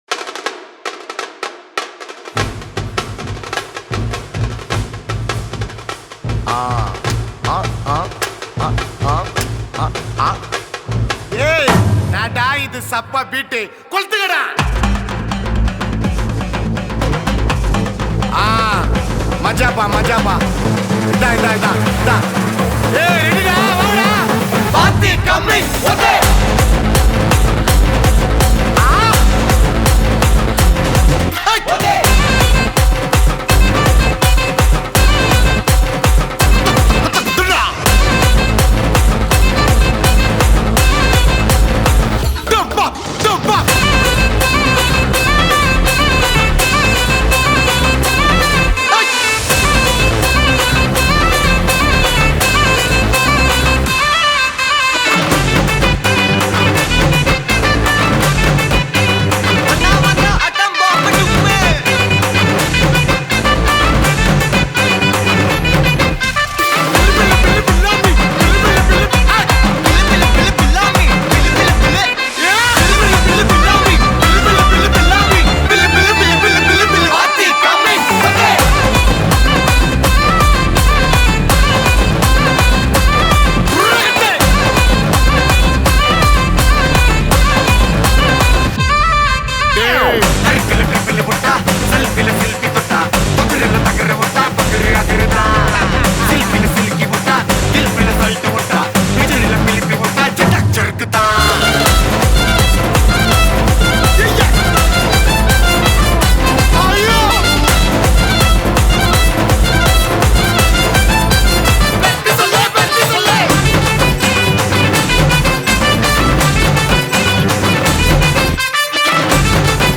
Shehnai
Flute
Drums Band
Dhol & Percussions
Keyboards, Synth and Rhythm